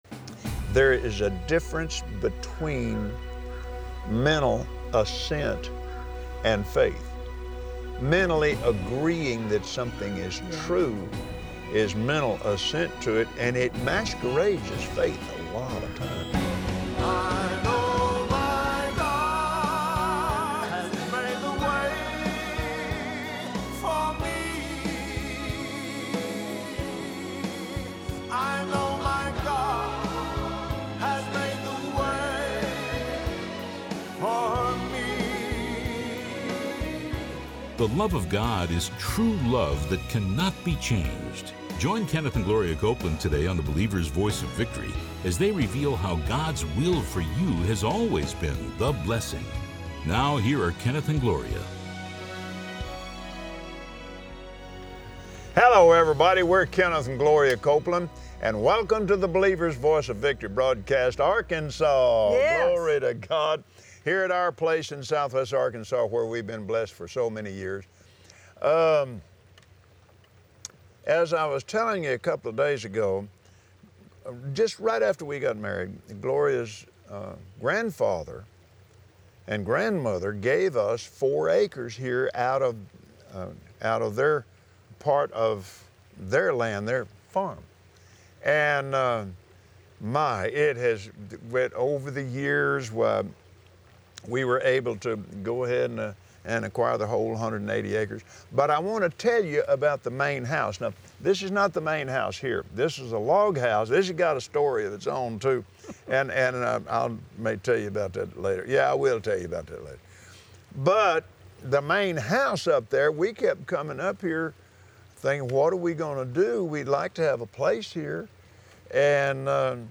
Believers Voice of Victory Audio Broadcast for Wednesday 06/21/2017 Love isn’t a feeling—it’s a covenant with power! Watch Kenneth and Gloria Copeland on Believer’s Voice of Victory share the power behind the covenant of God’s love that covers every area of your life.